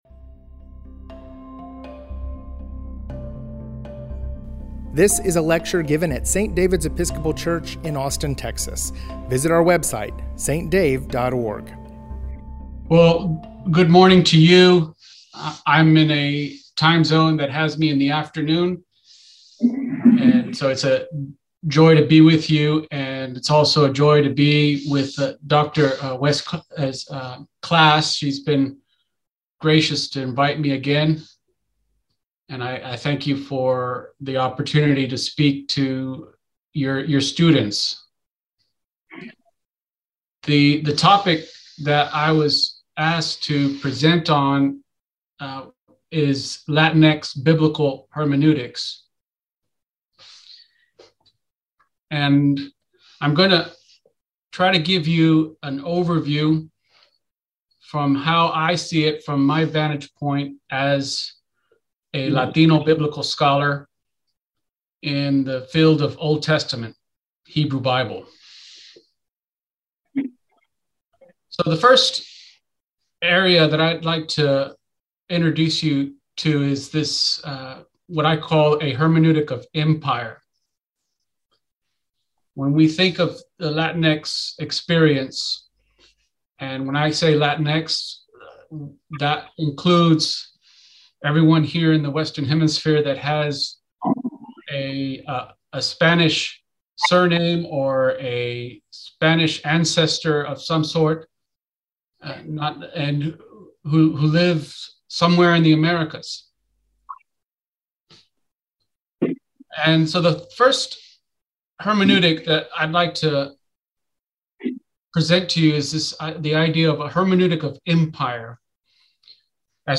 Biblical Hermeneutics Lecture Series: Latinx biblical hermeneutics